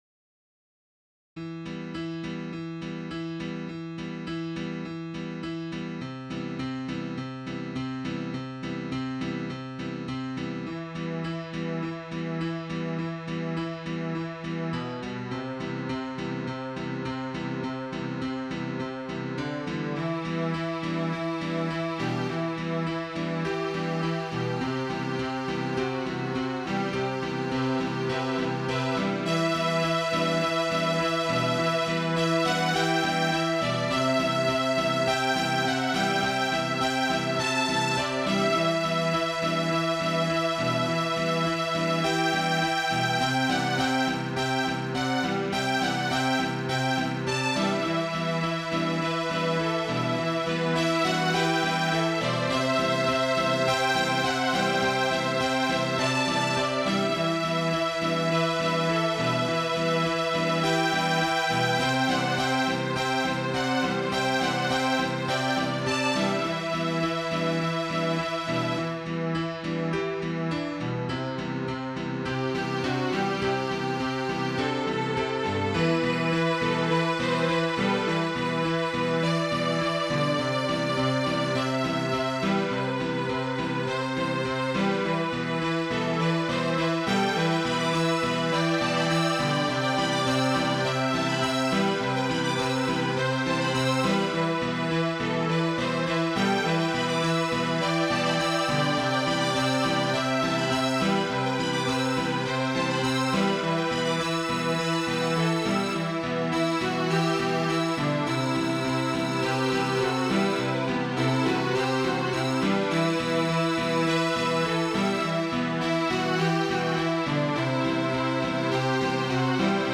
Title String Quartet Opus # 51 Year 0000 Duration 00:03:47 Self-Rating 2 Description I don't know what to make of this one.
After a hiatus, I listened again, and thought it sounded pretty good - as background music. Anything else, and the mind keeps waiting and waiting for the piece to take off, which it never does. Think of it as … a ride in the forest on horseback. mp3 download wav download Files: wav mp3 Tags: Quartet, Piano, Strings Plays: 1720 Likes: 0
051 String Quartet.wav